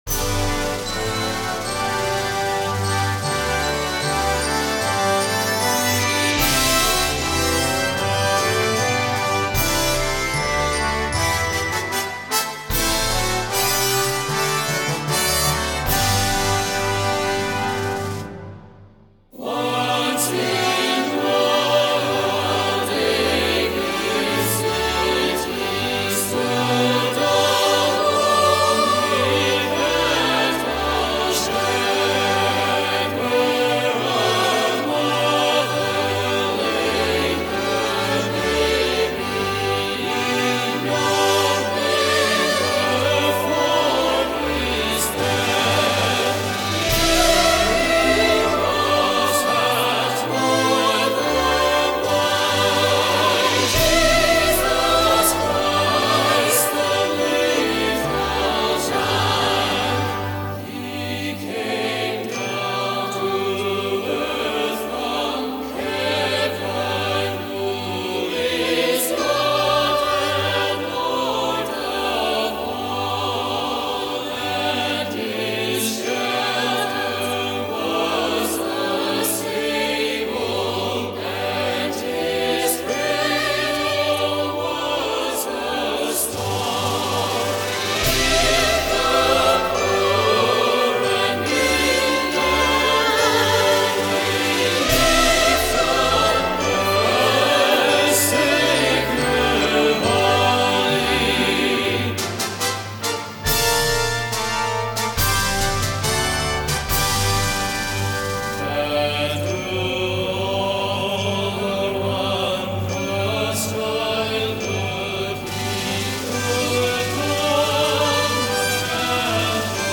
The choir parts are optional.
CategoryChristmas Music
InstrumentationPiccolo
Euphonium
Timpani
Glockenspiel
Tubular Bells